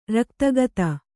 ♪ raktagata